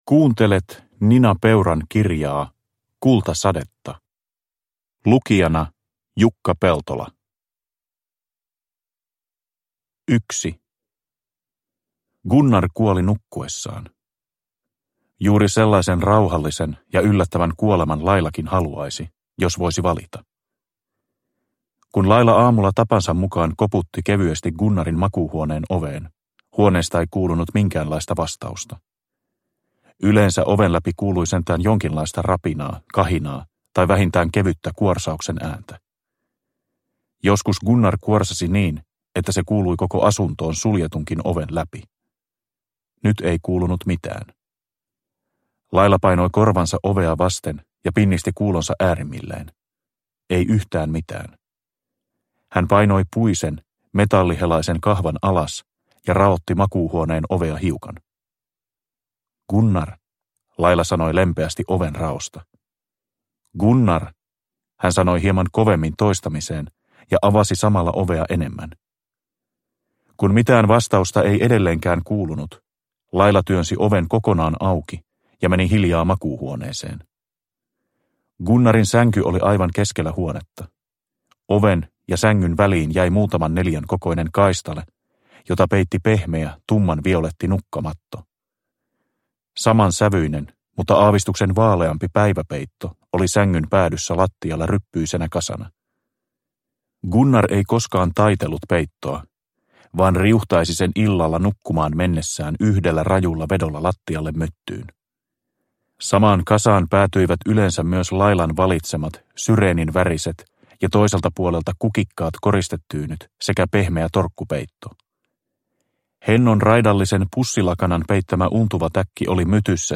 Kultasadetta – Ljudbok – Laddas ner